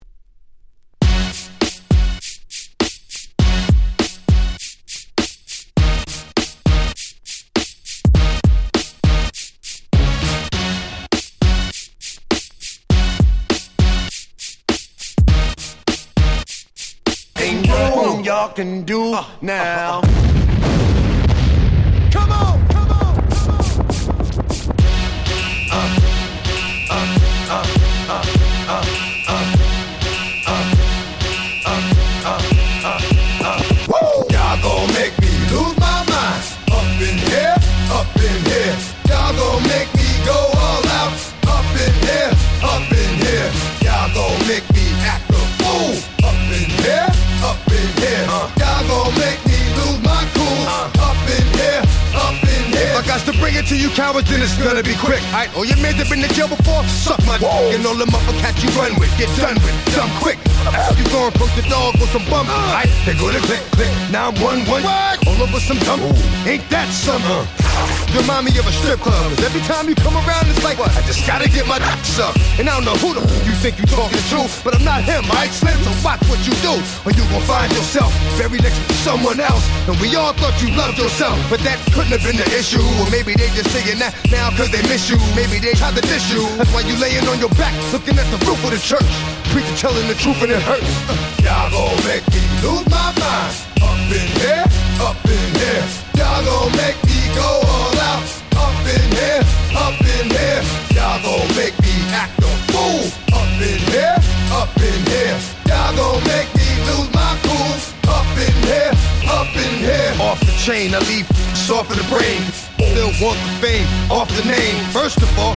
【Media】Vinyl 12'' Single
【Condition】C (スリキズ多めですがDJ Play可。チリノイズ出ます。)